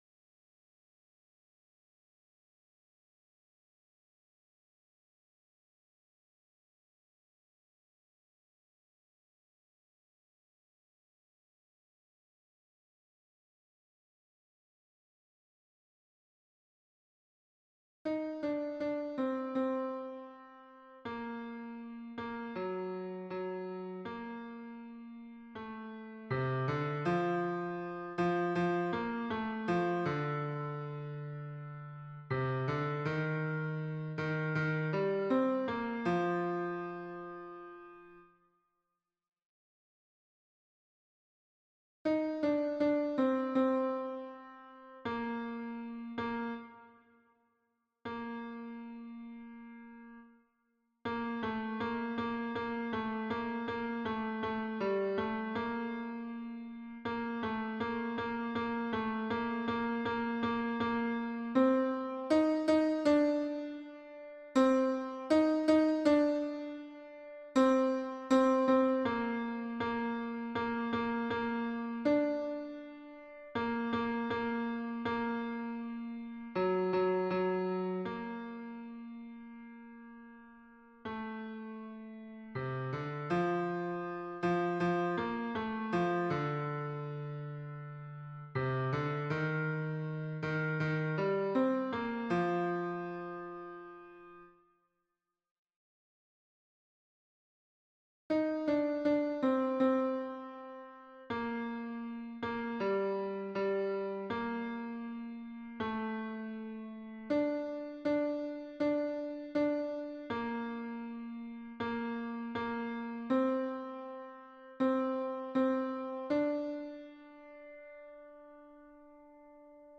Ténor